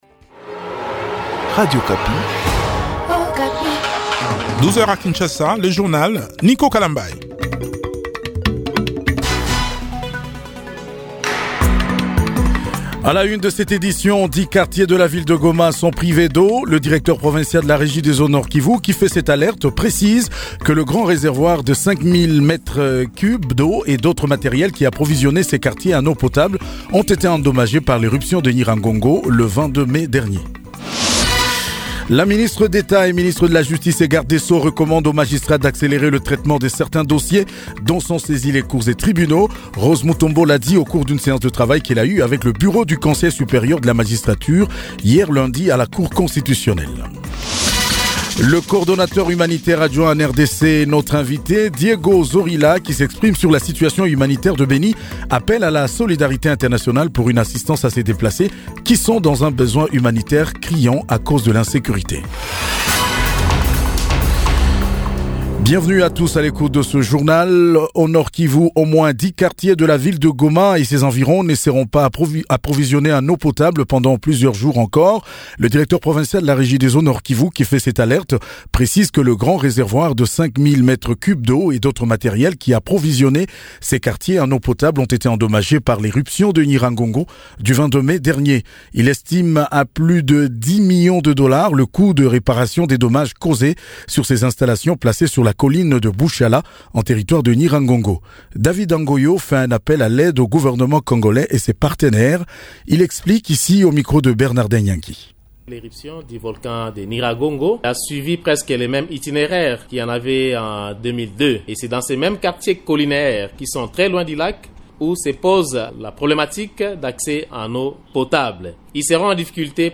JOURNAL MIDI DU MARDI 25 MAI 2021